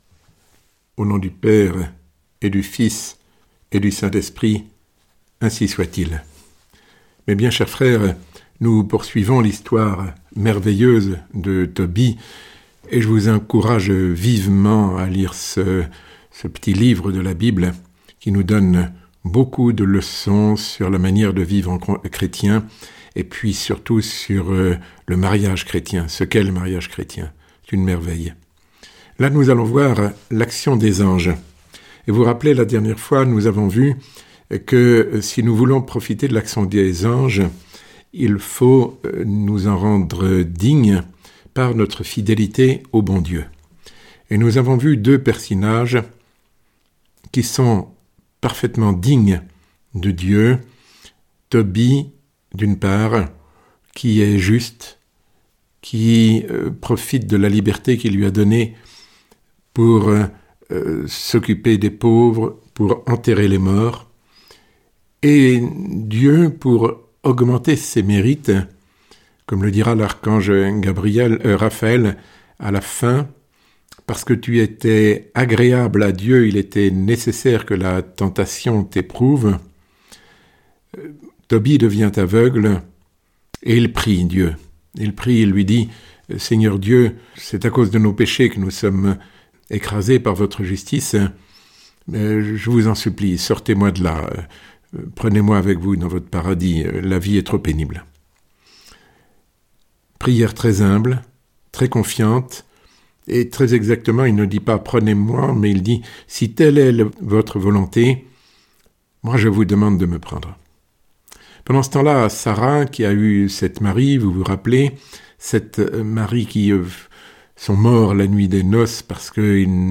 Sermon Les œuvres de Dieu ~ 8 Les bons anges à nos côtés ~ L’archange Raphaël et Tobie